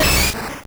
Cri de Machoc dans Pokémon Or et Argent.